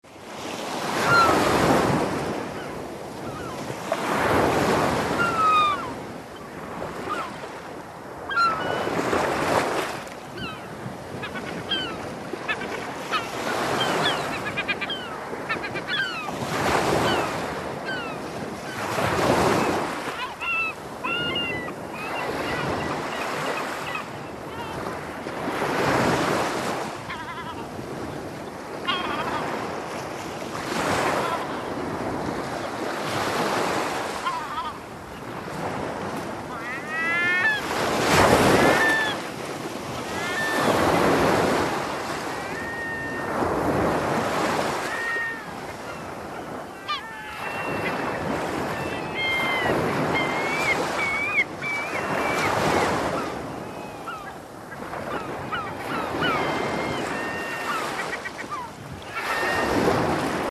Звуки пляжа
На этой странице собраны натуральные звуки пляжа: шум волн, легкий бриз, детский смех на песке и другие уютные аудиофрагменты.